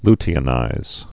(ltē-ə-nīz)